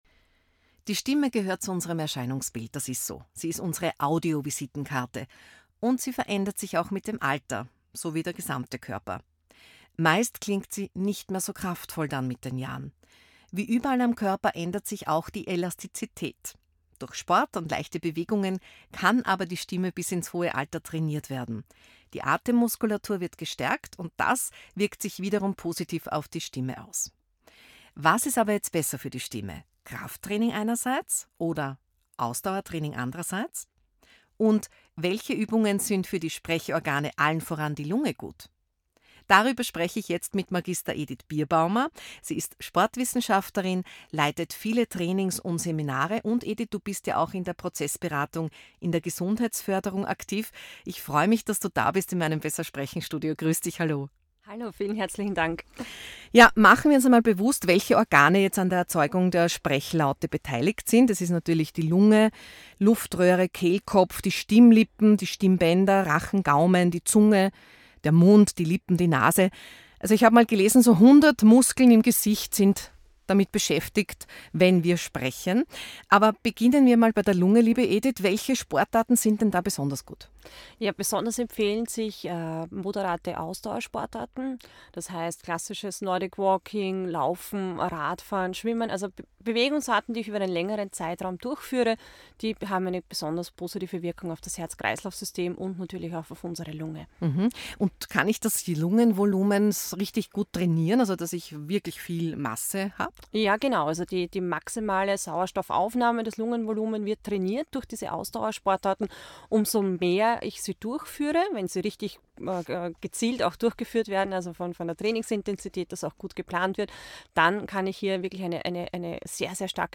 Ein Gespräch über Mut, Wirkung und persönliche Entwicklung.